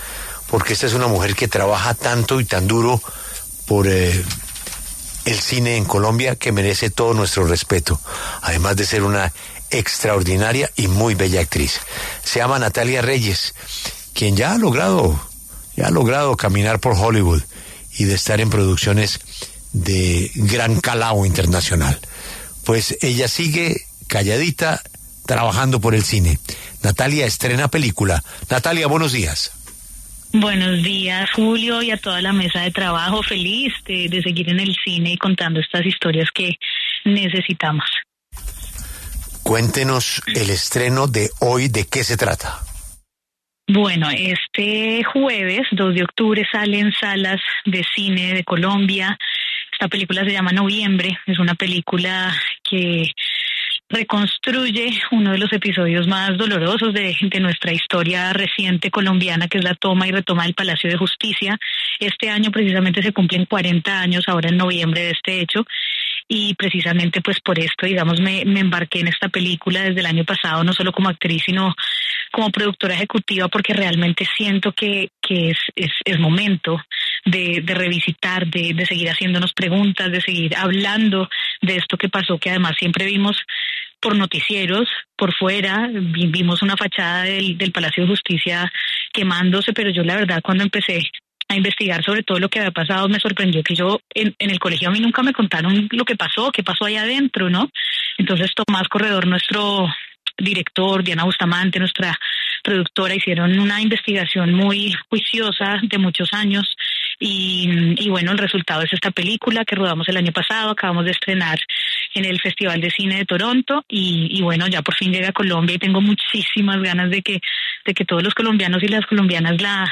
En La W, la actriz Natalia Reyes habló sobre la película ‘Noviembre’, que aborda la toma y la retoma del Palacio de Justicia en 1986.